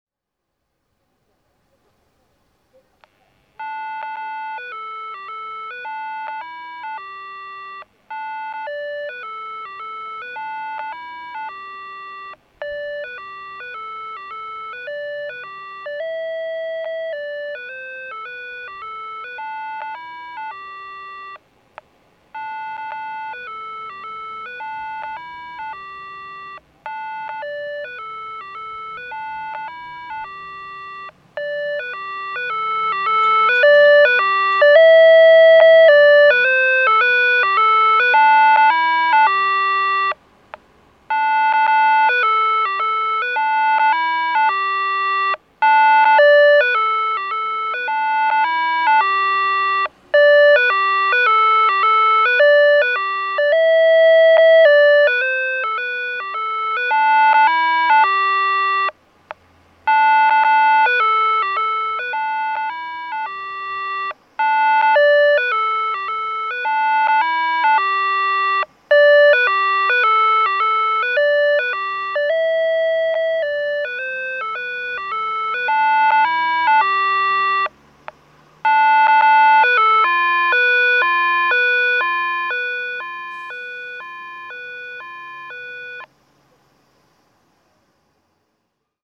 交通信号オンライン｜音響信号を録る旅｜神奈川県の音響信号｜[泉:028]泉区総合庁舎前
泉区総合庁舎前(神奈川県横浜市泉区)の音響信号を紹介しています。